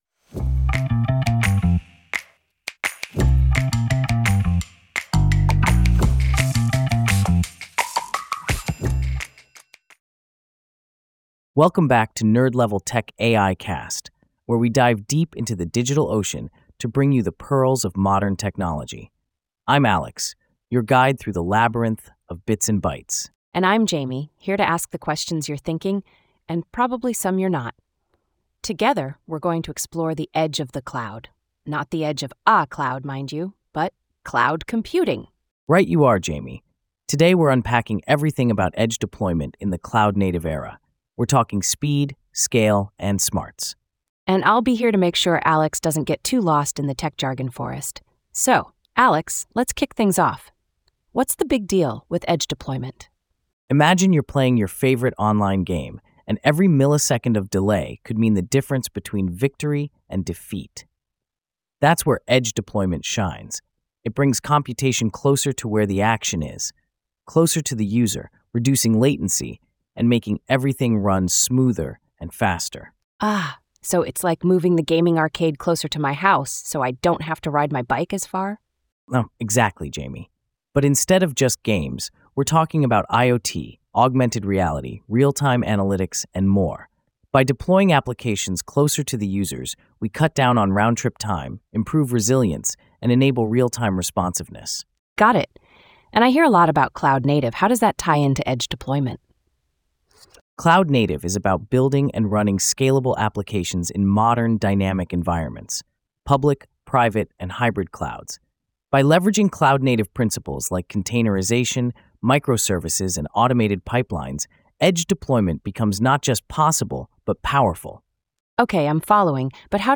AI-generated discussion by Alex and Jamie